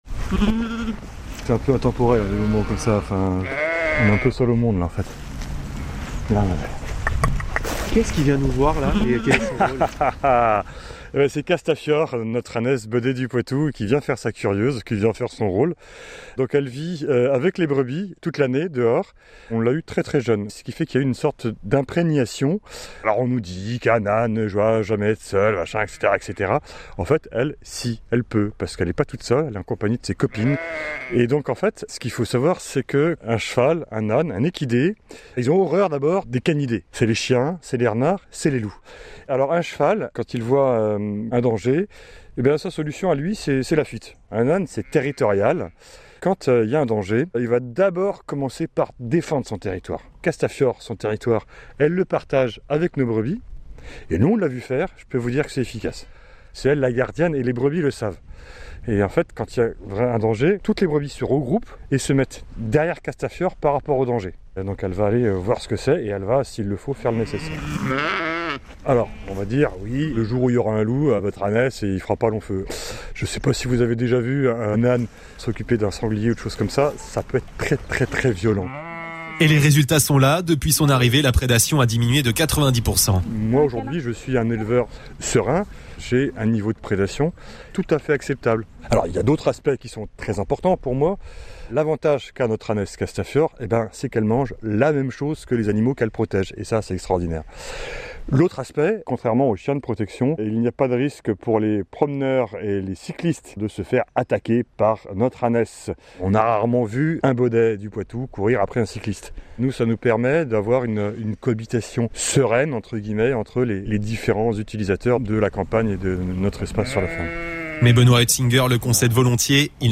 De notre envoyé spécial à Chemillé-en-Anjou,